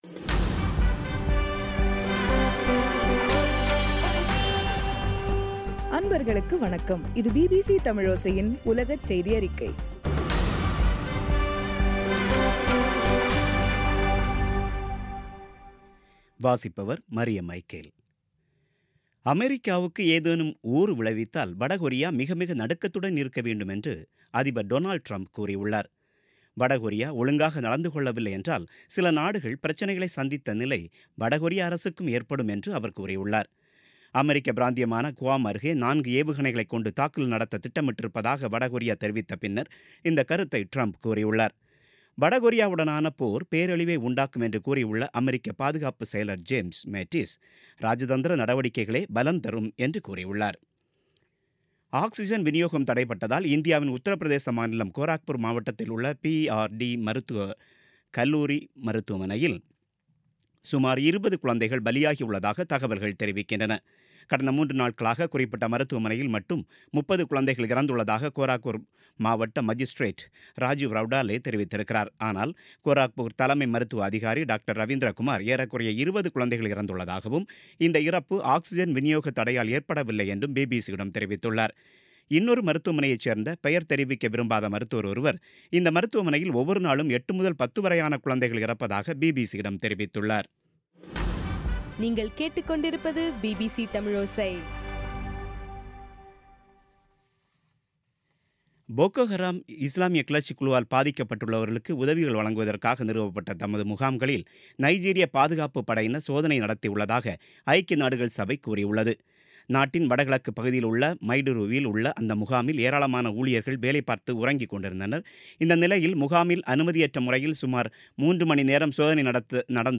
பிபிசி தமிழோசை செய்தியறிக்கை (11/08/2017)